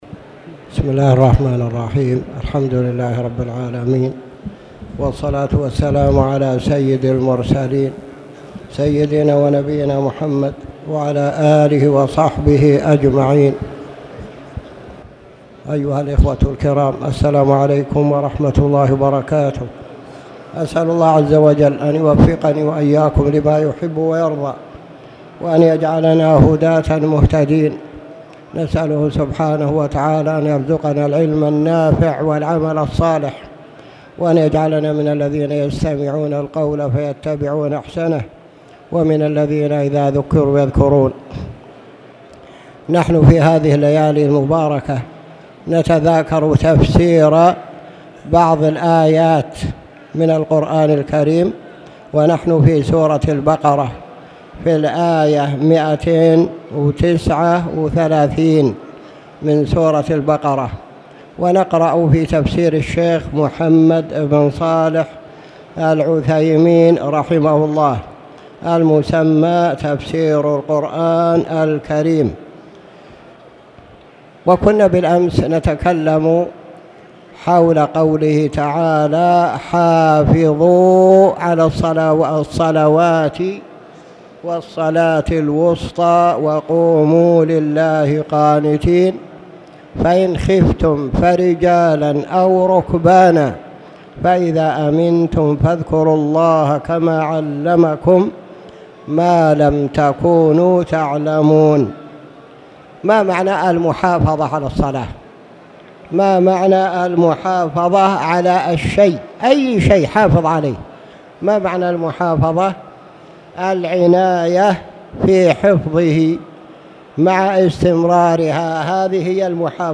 تاريخ النشر ٢٦ ذو الحجة ١٤٣٧ هـ المكان: المسجد الحرام الشيخ